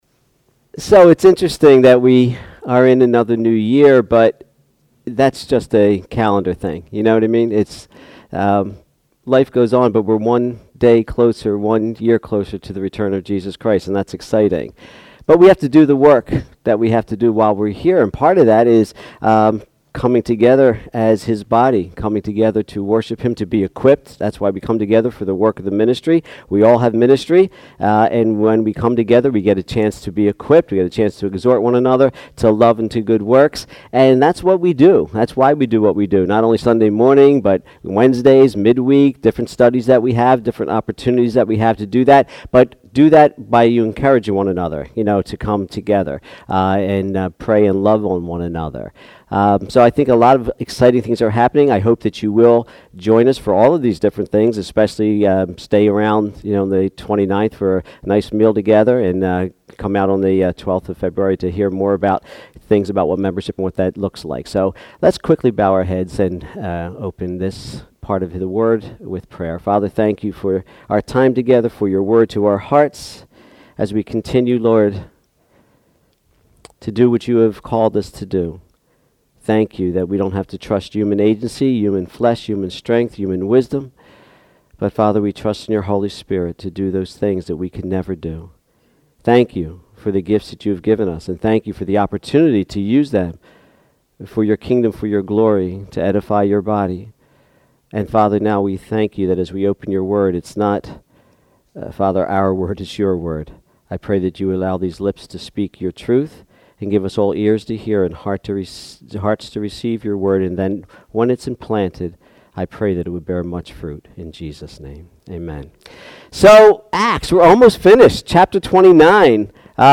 Sermons | Bethel Christian Church